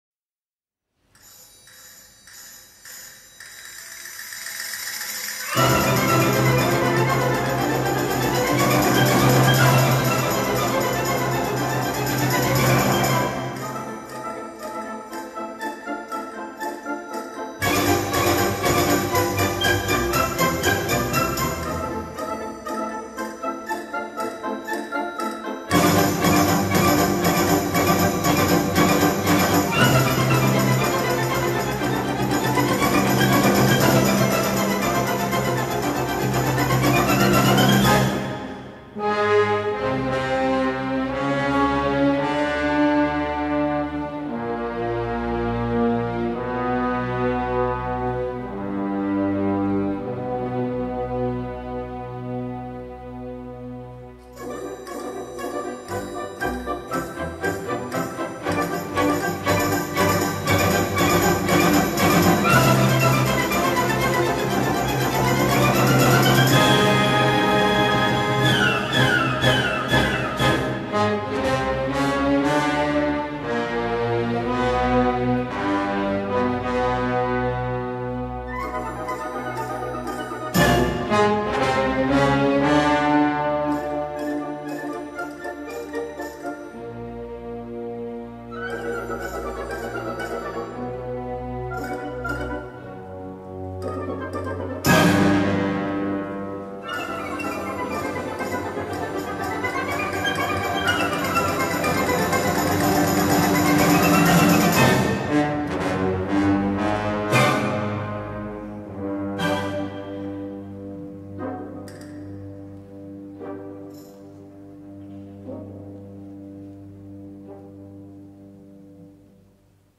Mark Wigglesworth, conductor
BBC Orchestra Wales
Just a bit…Mediterranean, wouldn’t you say?